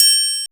MPC4 BELL.wav